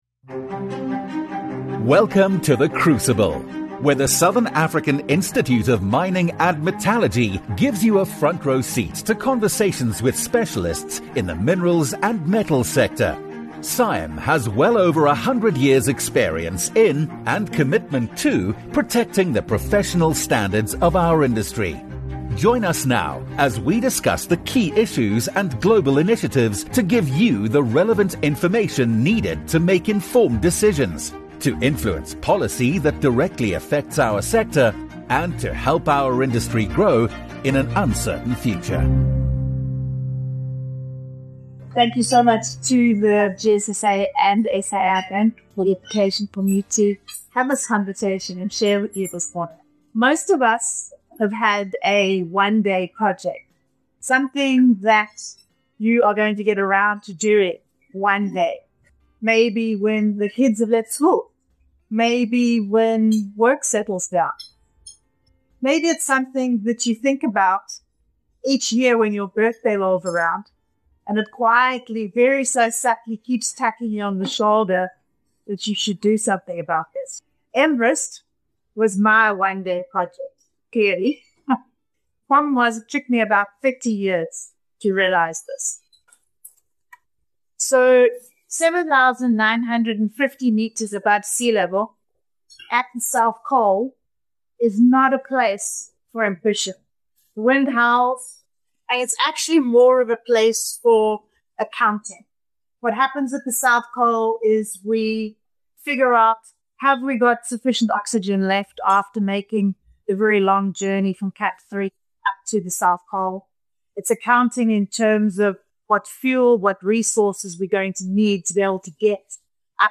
Welcome to The Crucible, where the Southern African Institute of Mining and Metallurgy gives you a front row seat to conversations with specialists in the Minerals and Metals sector.